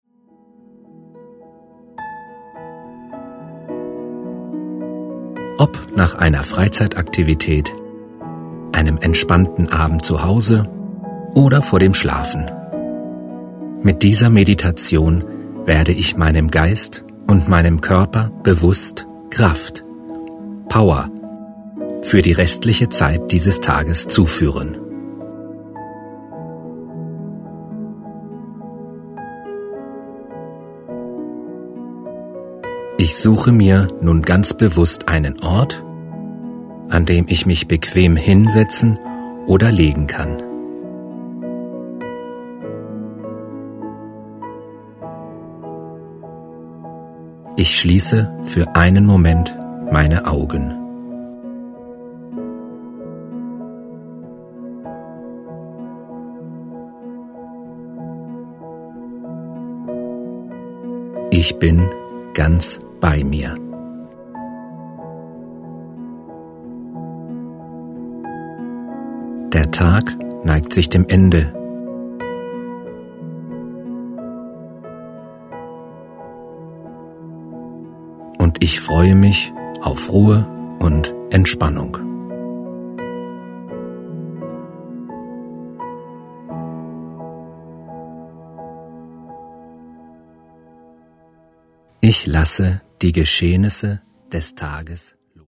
Die begleitende Musik beginnt belebter und wird nach und nach ruhiger.
Dem einen fällt die Entspannung bei einer tieferen männlichen Version leichter, den anderen inspiriert die weibliche ruhige Stimmlage.
Männliche Stimme   11:59 min